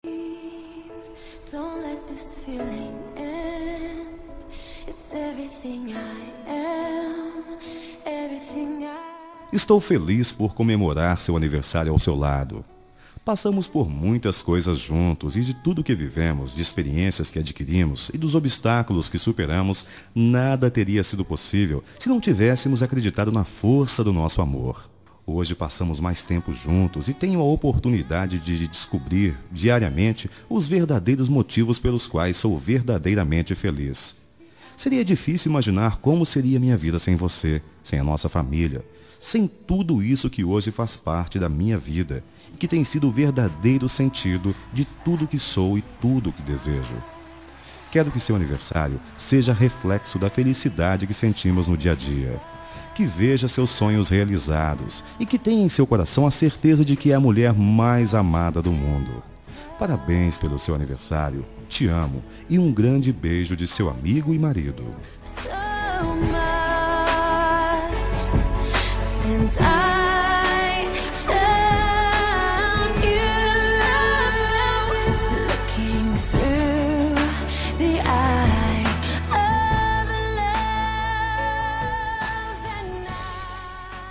Telemensagem de Aniversário de Esposa – Voz Masculina – Cód: 1114